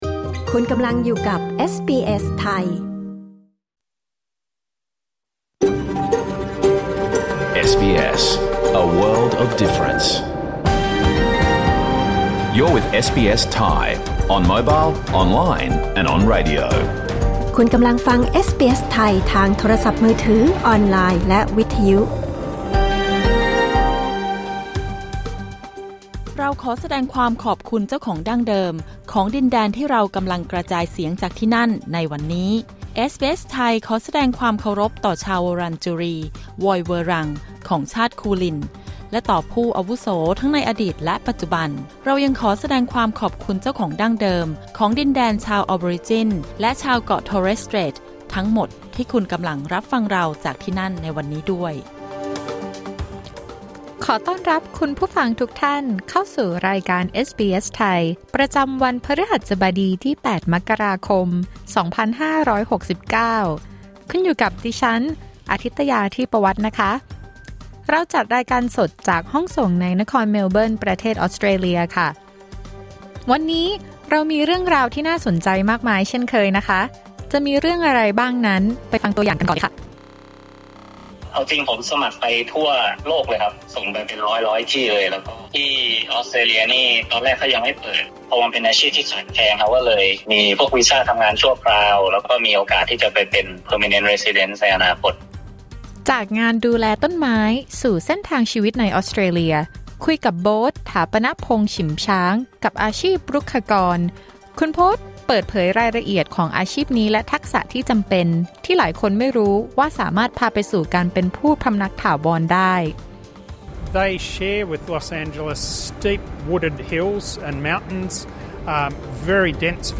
รายการสด 8 มกราคม 2569